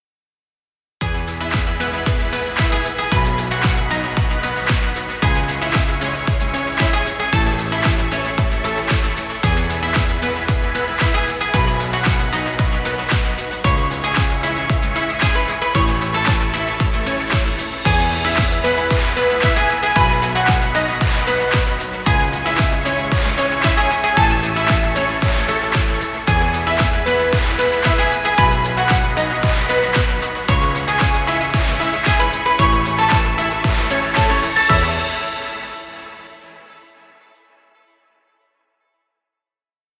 Happy positive music. Background music Royalty Free.